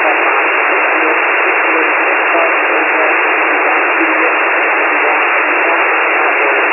Random sequences of 10 characters are generated in white noise, band
(0.0 dB S/N ratio)
Each file contains 10 random spelled out characters.